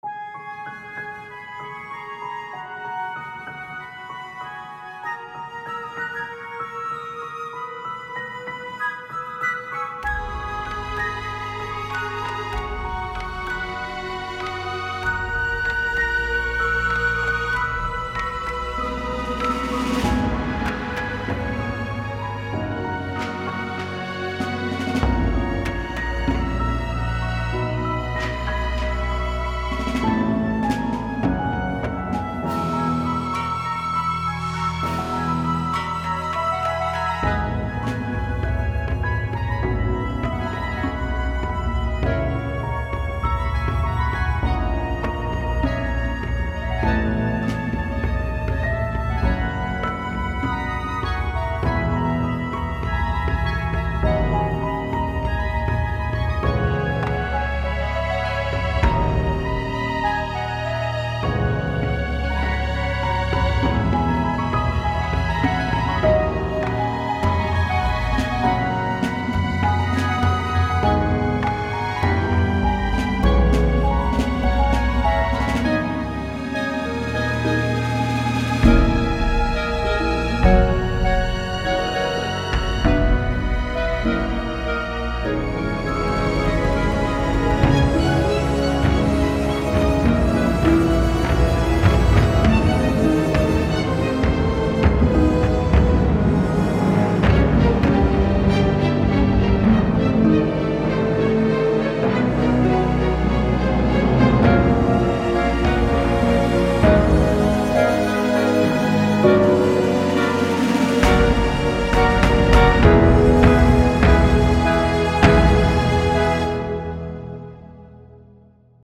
Kleiner aber feiner Soundtrack
Ich habe den EQ entfernt und die Lautstärke der höheren Töne manuell angepasst.
Das Piano und die Harfe habe ich mittig gepannt, um die räumliche Verteilung zu optimieren.
Am Ende kommt ein etwas bedrohlicherer Part. Die Dynamik bei der Percussion ist vollständig vorhanden, bei den Instrumenten fehlt die Dynamik im neuen Teil größtenteils noch.